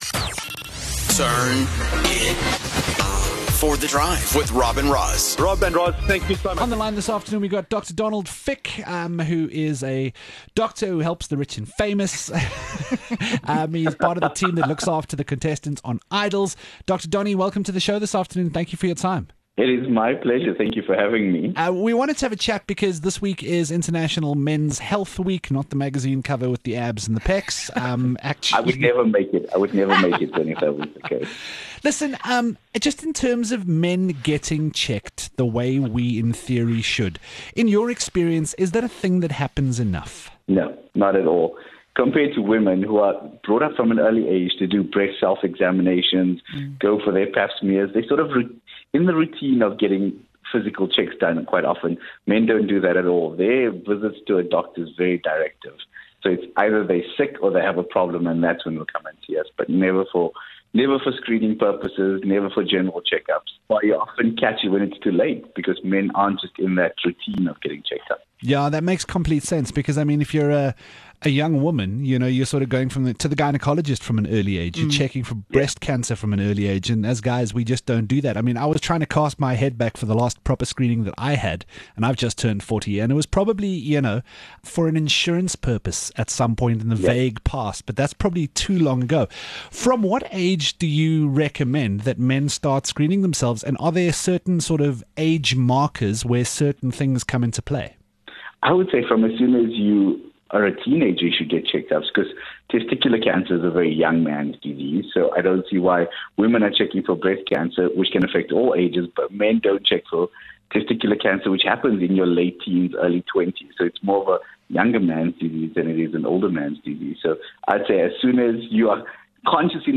And more questions that need answers were covered in this in-depth discussion.